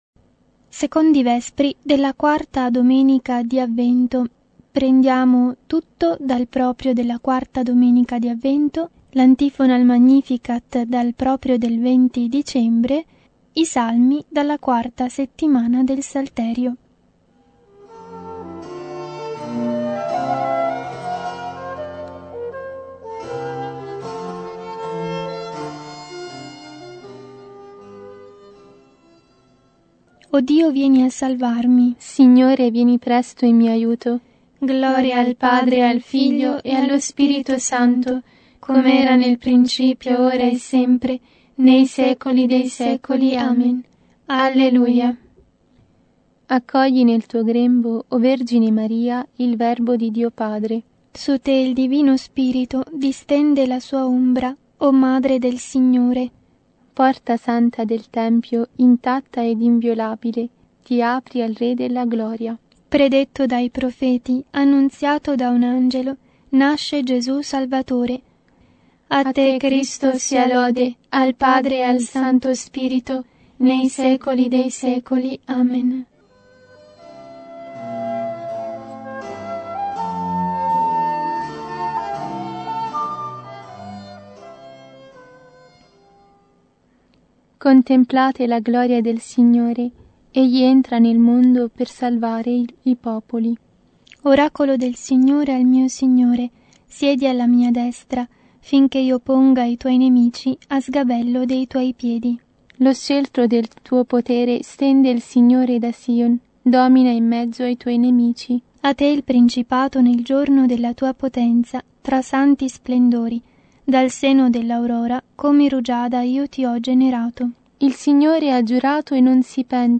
Genere: VESPRI.